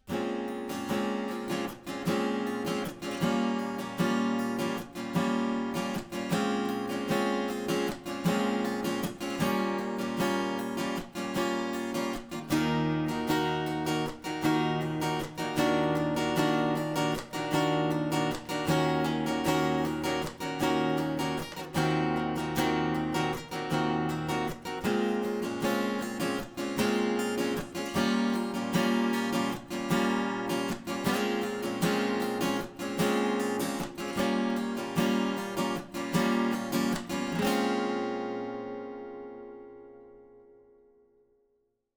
Guitare électro-acoustique :
Rythmique avec microphone externe :